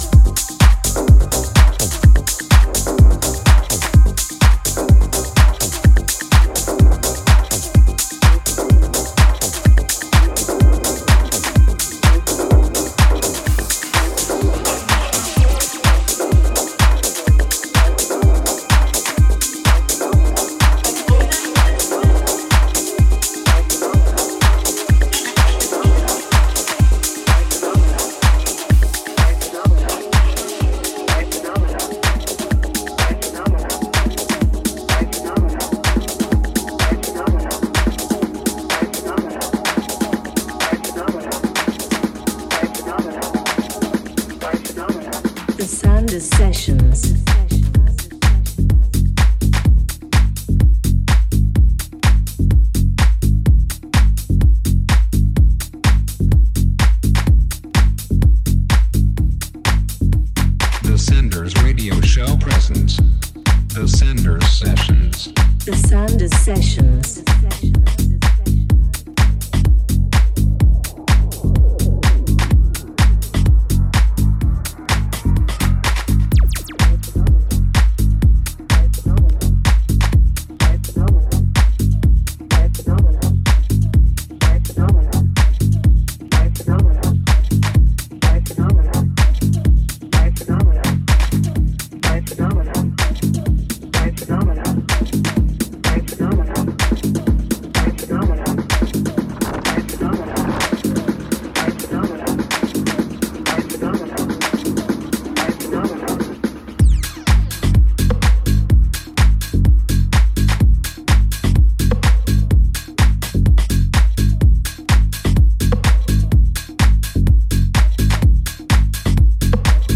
one hour of good sounds mixed